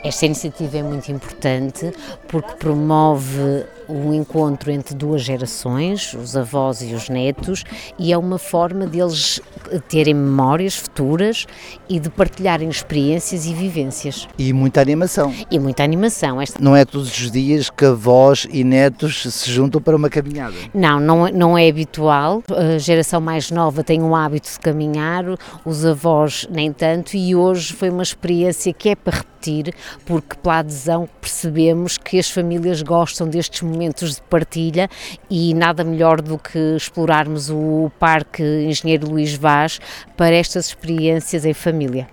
A Vereadora do Pelouro da aérea Social, Susana Viana, destacou a interação entre as duas gerações é crucial para uma perfeita harmonia intergeracional: